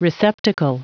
Prononciation du mot receptacle en anglais (fichier audio)
Prononciation du mot : receptacle